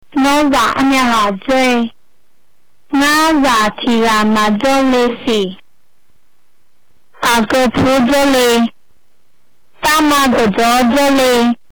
Here’s a recording of a mystery language.